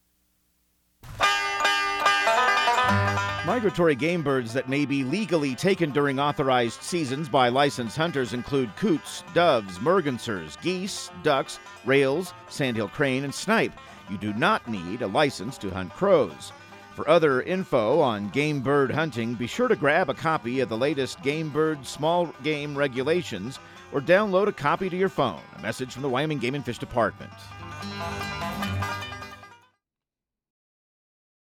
Radio news | Week of February 24
Outdoor Tip/PSA